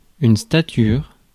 Ääntäminen
Ääntäminen US : IPA : [ˈstætʃ.ɚ]